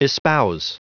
Prononciation du mot espouse en anglais (fichier audio)
Prononciation du mot : espouse